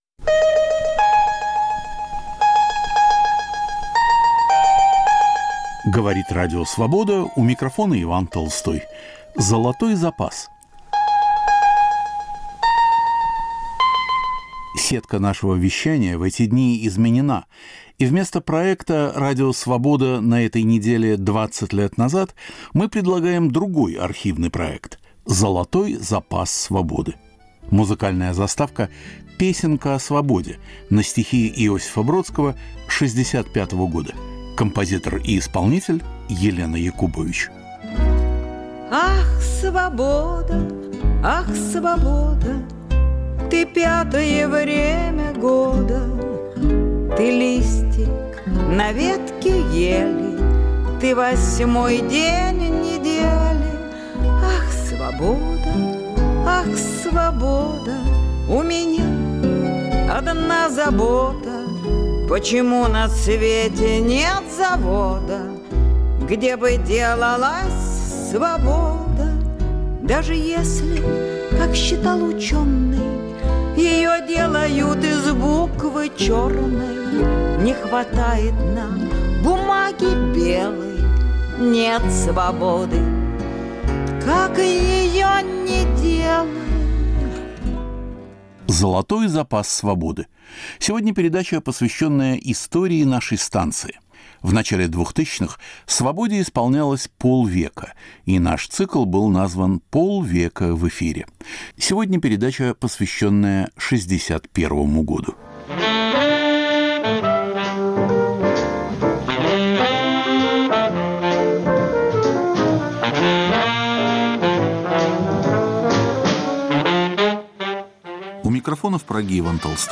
Цикл передач к 50-летию Радио Свобода. Из архива: полет Юрия Гагарина, 20-летие нападения Германии на СССР, беседа Нины Берберовой о Владиславе Ходасевиче, интервью с актрисой Лилей Кедровой, антисталинский съезд КПСС, Берлинская стена, музыкальный фестиваль в Сан-Ремо.